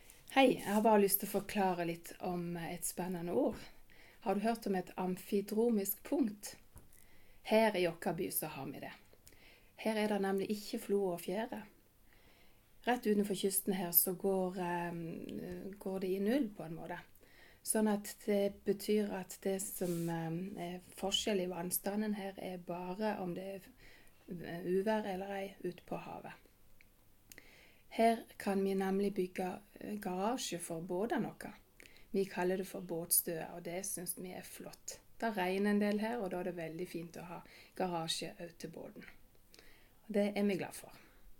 Norwegian dialect from Flekkefjord